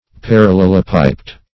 Parallelopiped \Par`al*lel`o*pi"ped\